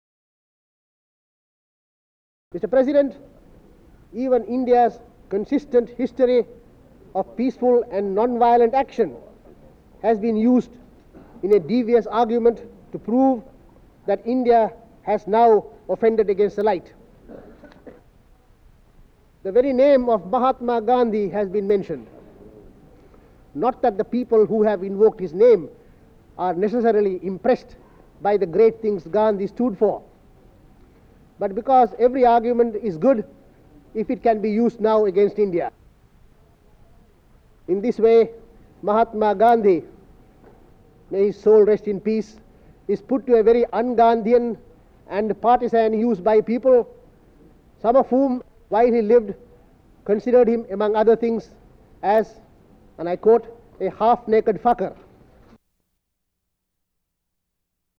An unidentified U.N. Indian delegate describes how Mahatma Gandhi's legacy is used by people to argue against the current state of India despite those same people describing Gandhi as "a half-naked fakir" while he was alive.